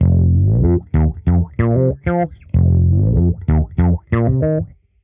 Index of /musicradar/dusty-funk-samples/Bass/95bpm